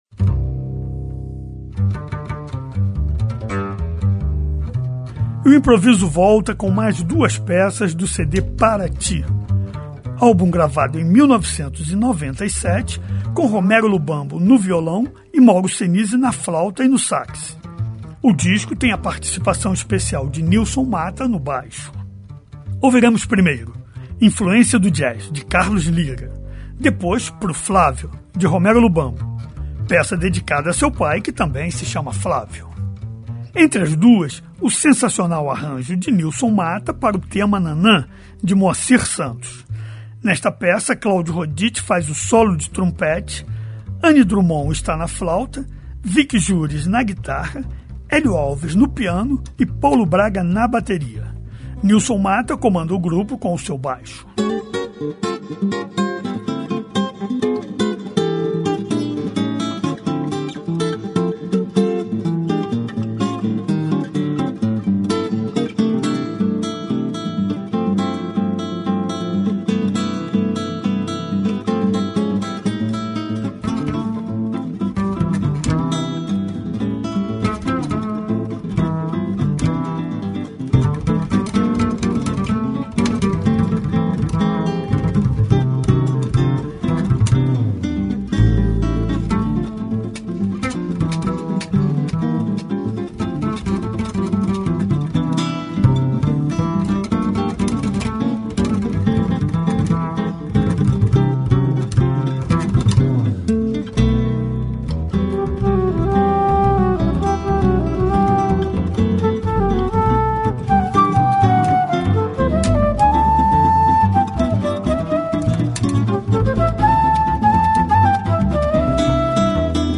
Programa semanal sobre a música instrumental brasileira